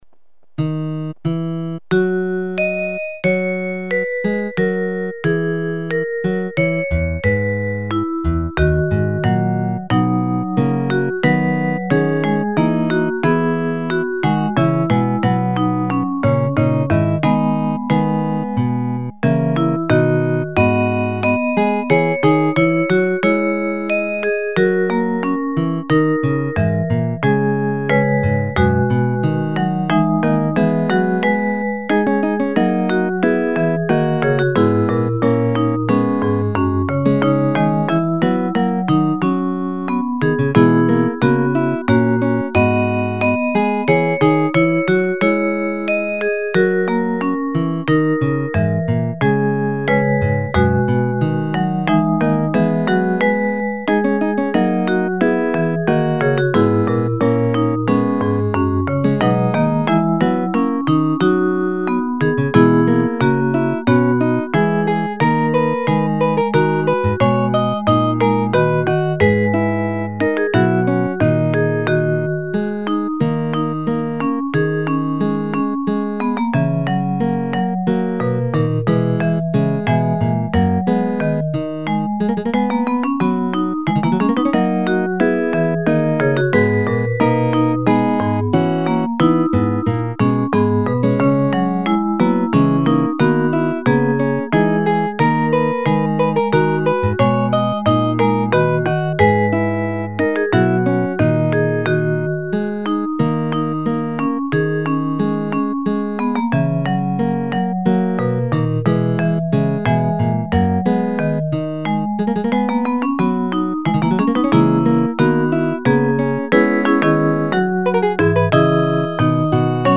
this was composed after a traditional folk tune of Brazil
POLYPHONIC MUSIC